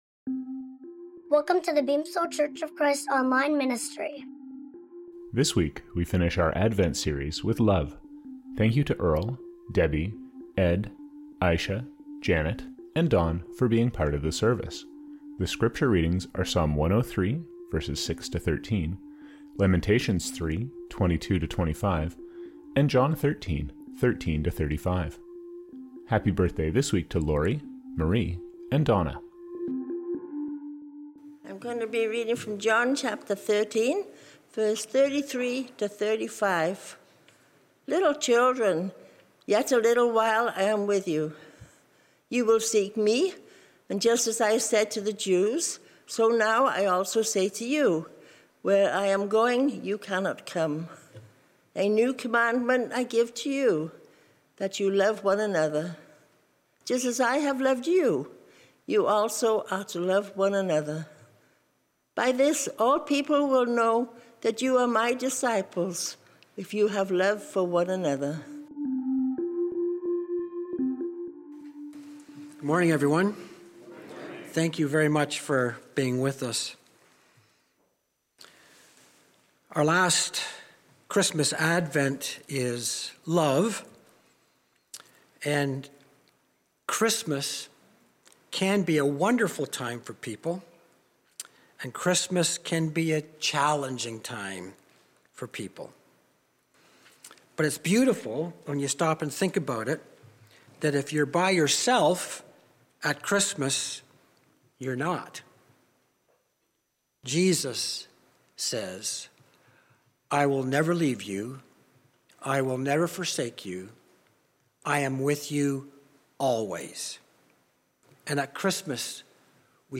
The weekly sermon from the Beamsville Church of Christ